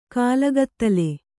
♪ kālagattale